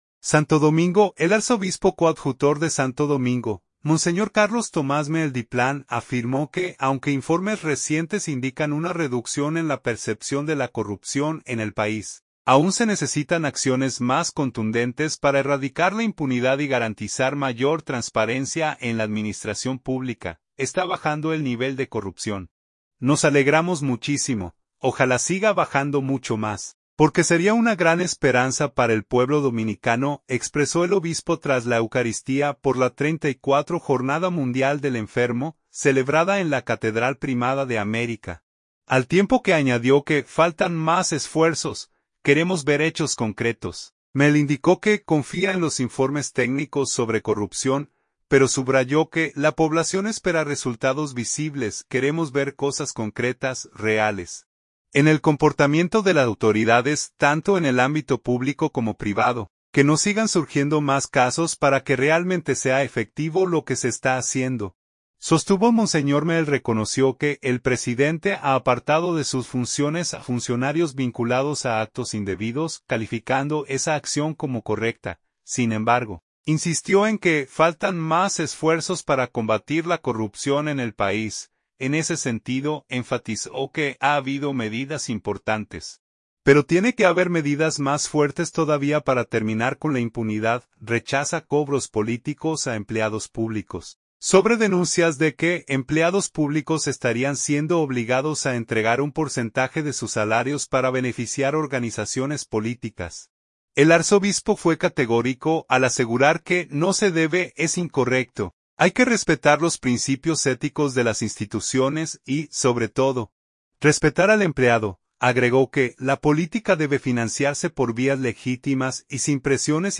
“Está bajando el nivel de corrupción, nos alegramos muchísimo. Ojalá siga bajando mucho más, porque sería una gran esperanza para el pueblo dominicano”, expresó el obispo tras la eucaristía por la XXXIV Jornada Mundial del Enfermo, celebrada en la Catedral Primada de América, al tiempo que añadió que "faltan más esfuerzos".